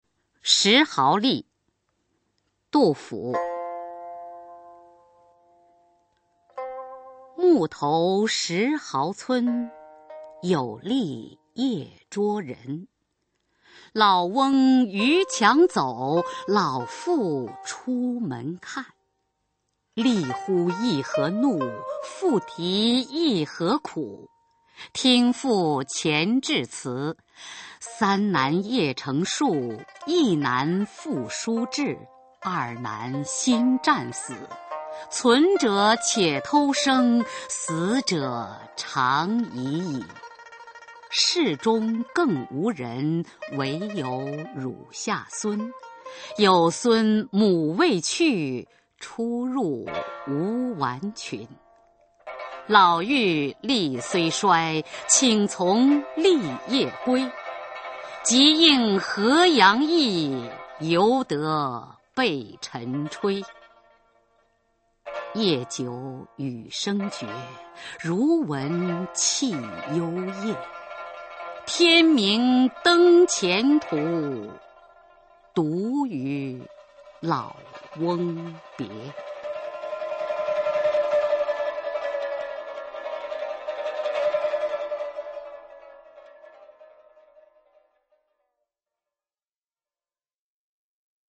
[隋唐诗词诵读]白居易-石壕吏 唐诗诵读